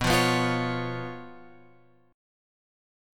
B Suspended 2nd Flat 5th